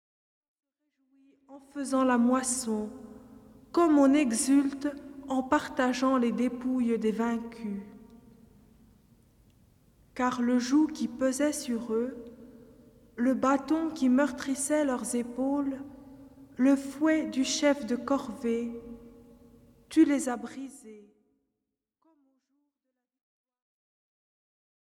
(02:06) Lecture - Isaïe 9, 1-6 - Noël (+0.99 EUR)